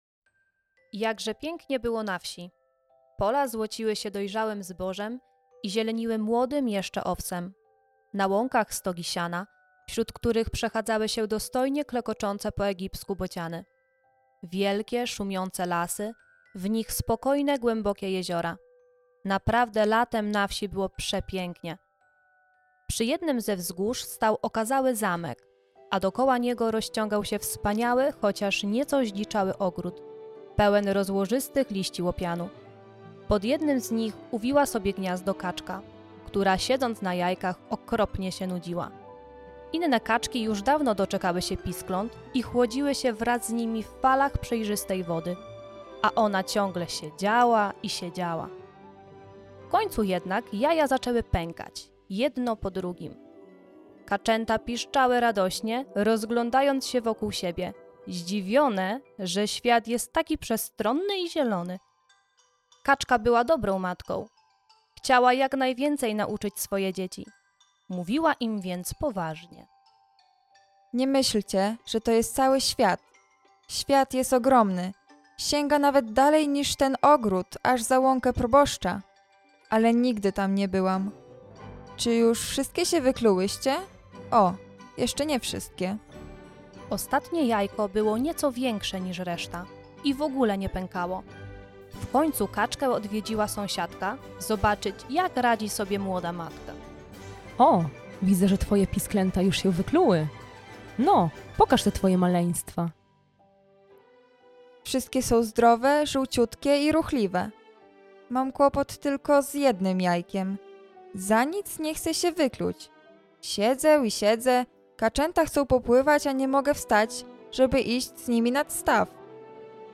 Bajki i wiersze czytają dla Was studenci PWSTE.